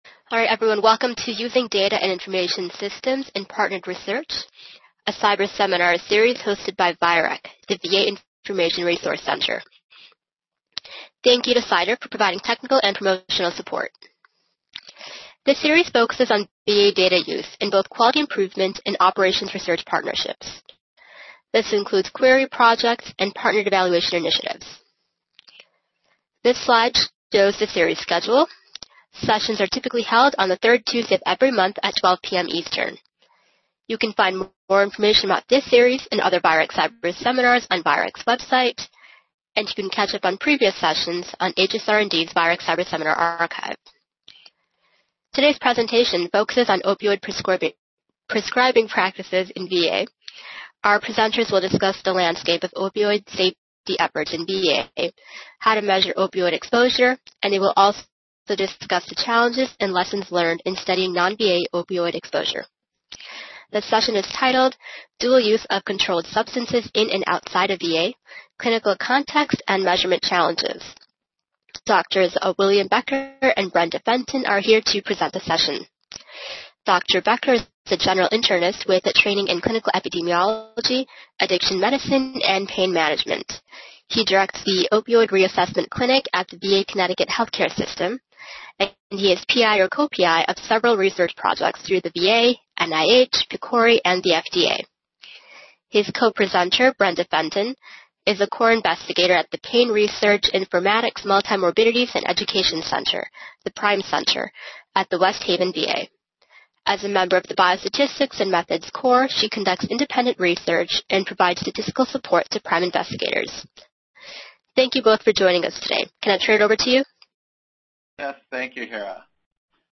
MSc Seminar date